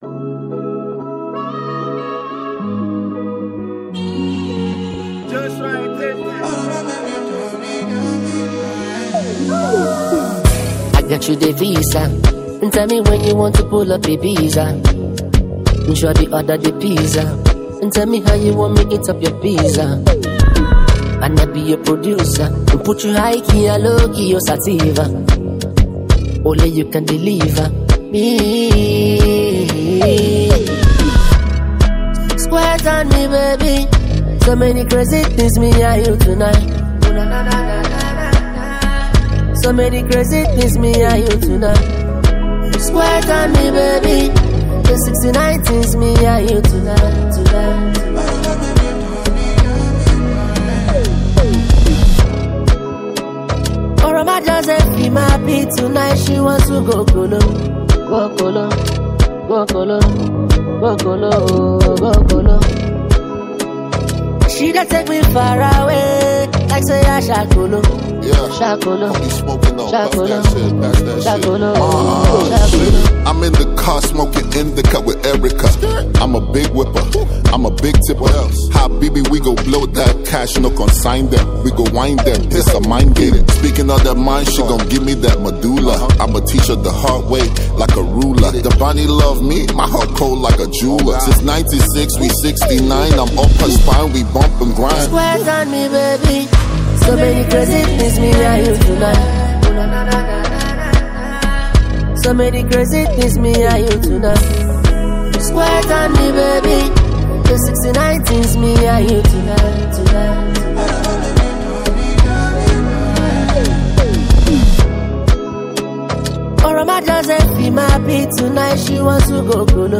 is an exciting blend of Afrobeat and contemporary sounds
blending Afrobeat, dancehall, and urban sounds